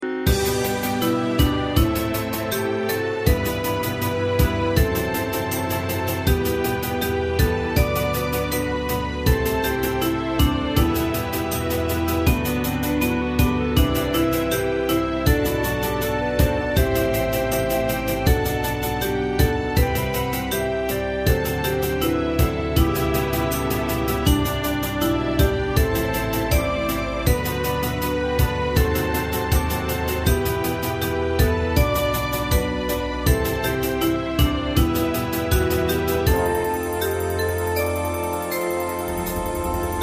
Ensemble musical score and practice for data.